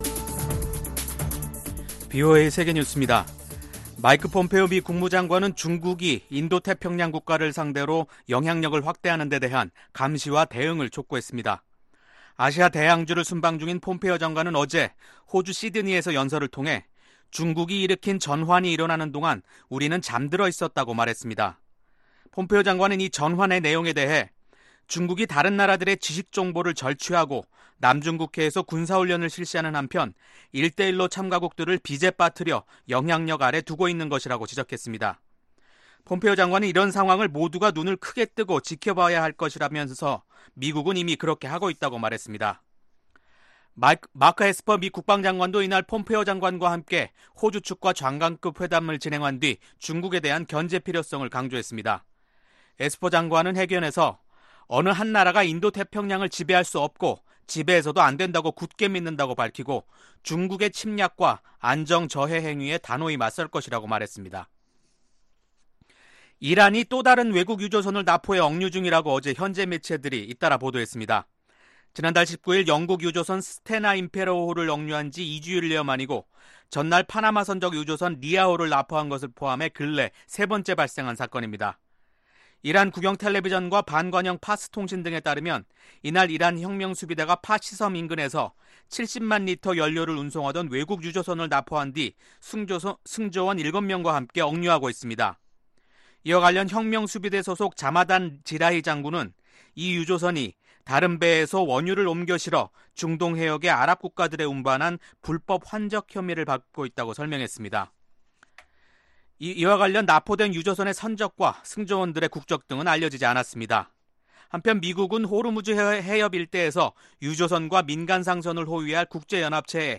VOA 한국어 간판 뉴스 프로그램 '뉴스 투데이', 2019년 8월 5일3부 방송입니다. 미군과 한국군의 연합군사훈련이 5일 시작됐습니다. 전문가들은 북한의 강도 높은 반발을 예상하고 있지만 아직까지 북한의 별다른 움직임은 나타나지 않았습니다. 미 국무부 고위관리는 최근 북한의 도발을 ‘큰 실수’이자 피해를 자초한 것이라는 국제사회의 공통된 견해가 있었다고 밝혔습니다.